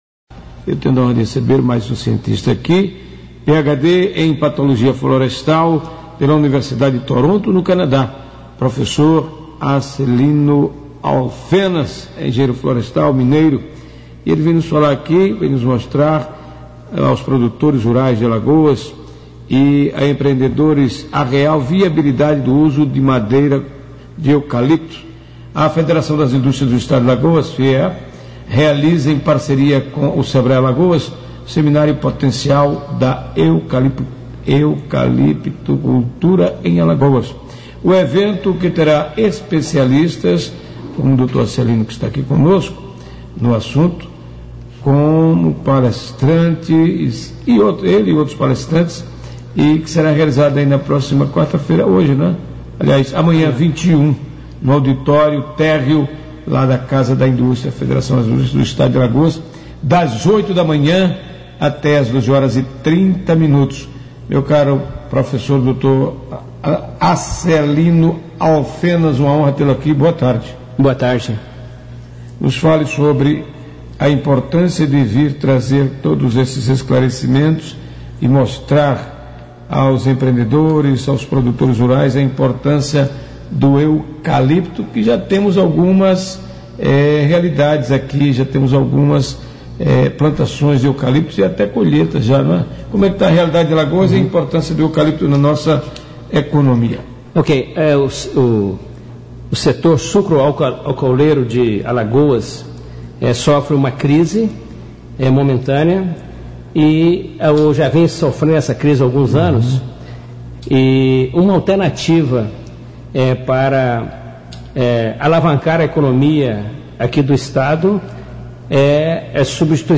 20-02-rádio-gazeta_eucalipto.mp3